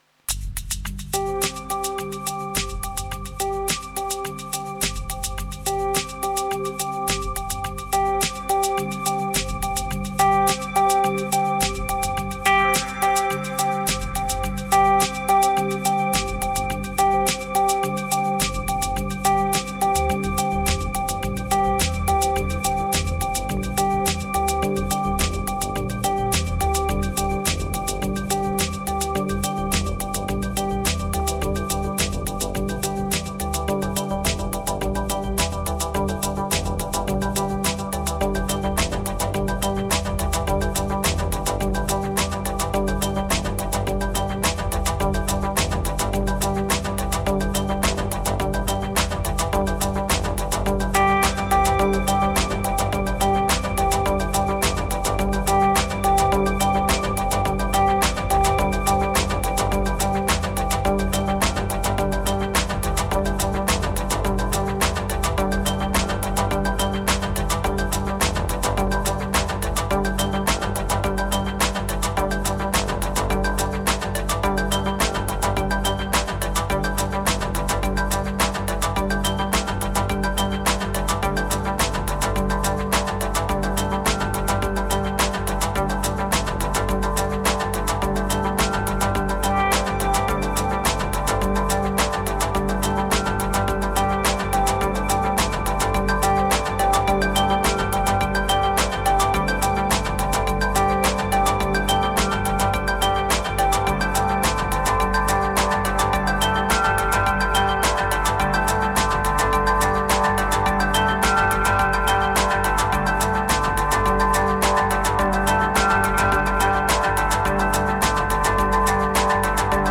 82📈 - 93%🤔 - 106BPM🔊 - 2026-03-10📅 - 385🌟
Felt liberating but lost the bassline mojo in the mix.